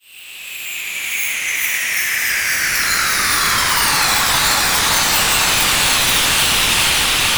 Fx.wav